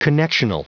Prononciation du mot connectional en anglais (fichier audio)
Prononciation du mot : connectional